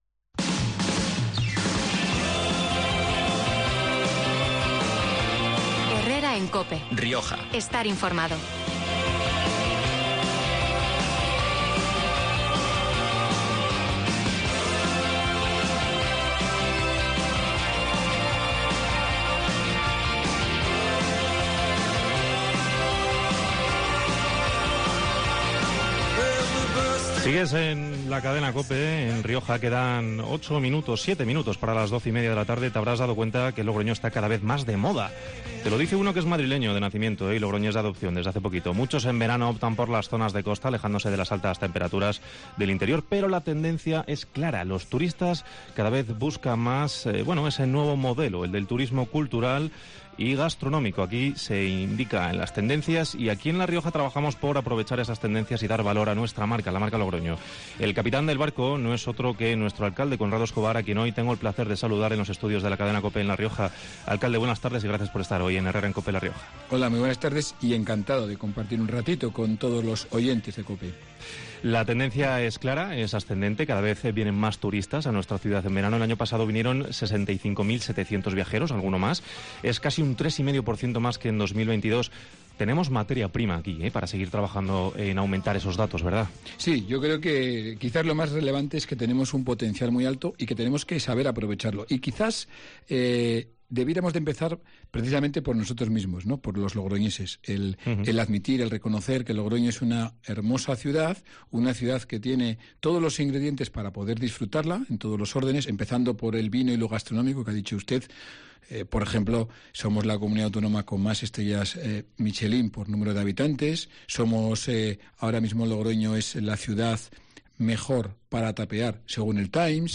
El alcalde de Logroño, Conrado Escobar, ha estado en los estudios de COPE analizando el potencial turístico de la capital de La Rioja